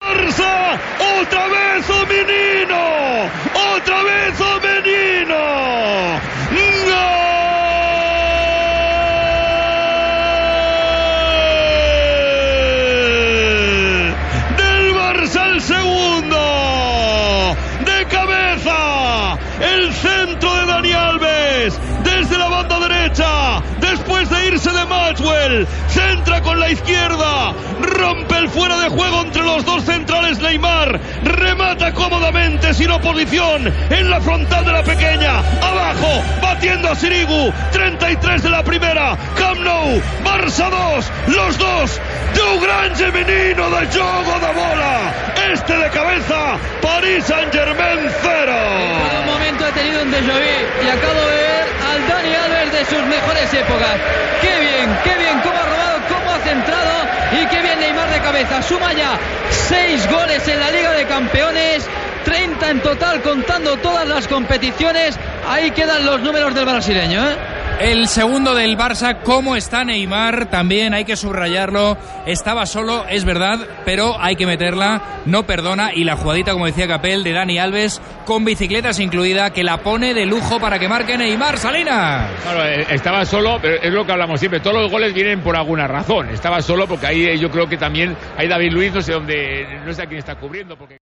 Transmissió del partit de quarts de final de la Copa d'Europa de Futbol masculí entre el Futbol Club Barcelona i el Paris Saint Germain.
Descripció i valoració del segon gol de Neymar.